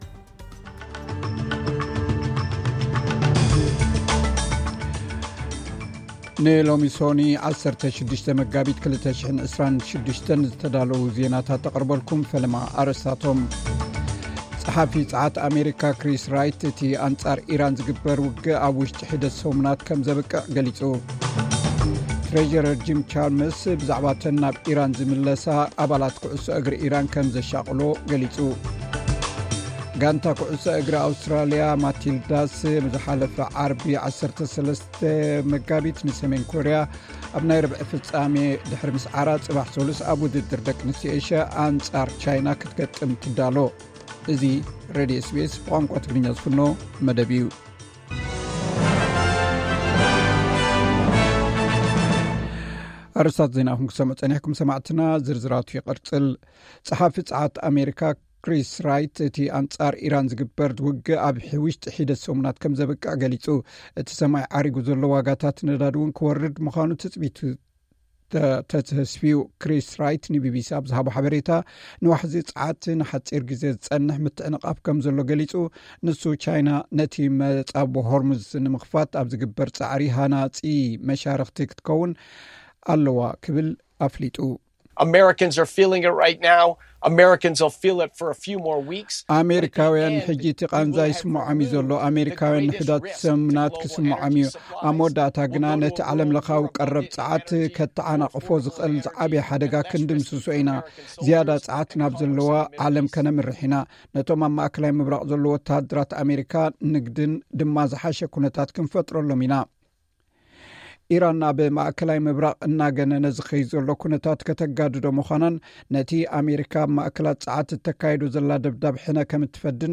ዕለታዊ ዜና SBS ትግርኛ (16 መጋቢት 2026)